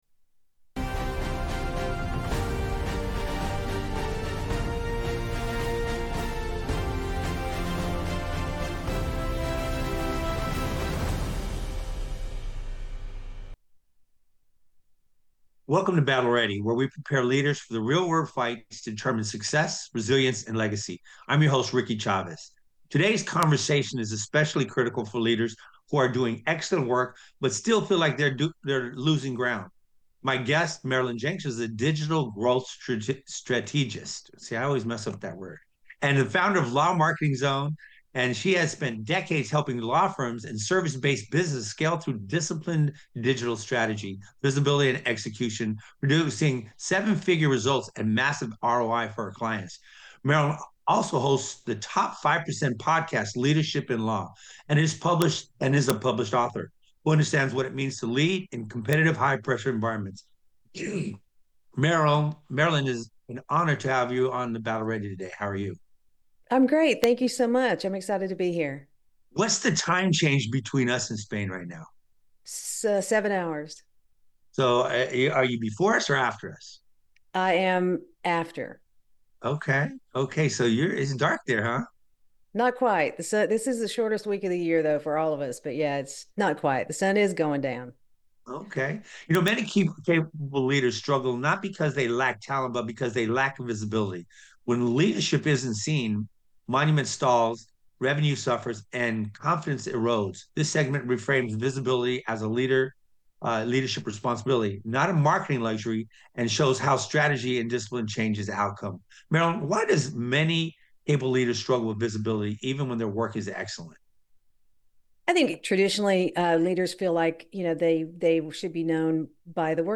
The conversation covers social media visibility, YouTube and Google strategy, leadership reinvention, delegation under pressure, and building accountable, mission-ready teams.